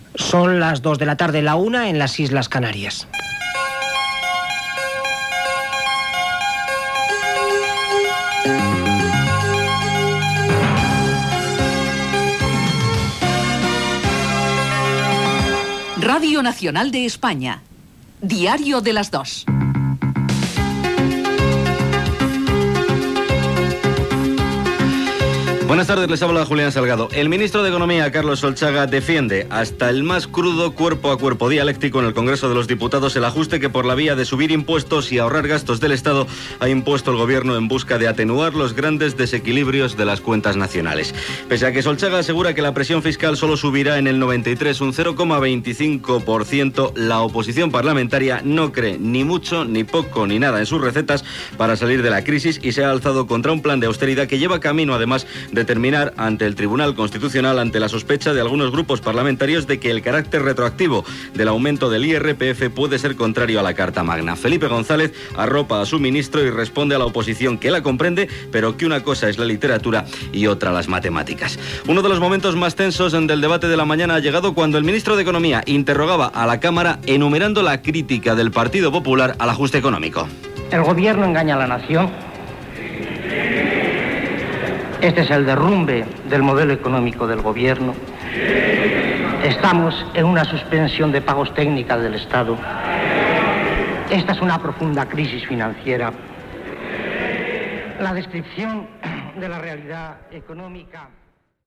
Hora, careta, el ministre d'Economia Carlos Solchaga i les seves propostes d'ajust econòmic
Informatiu